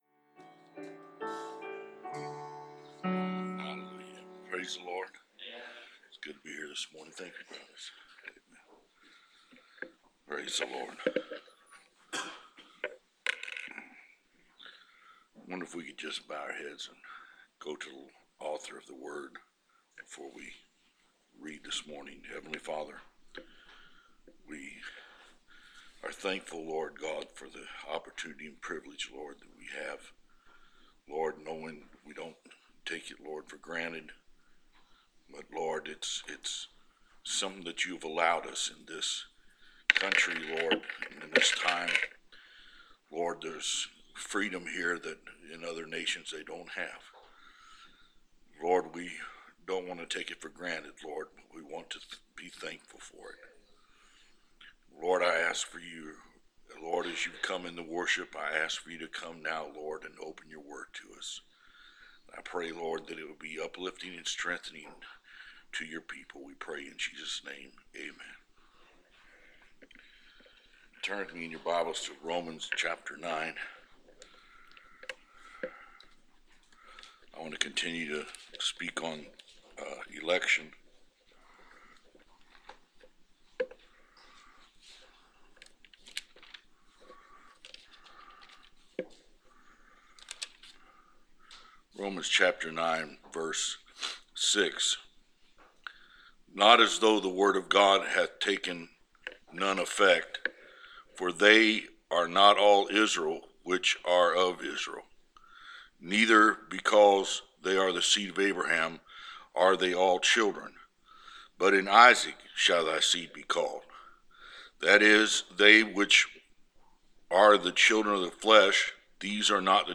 Preached November 13, 2016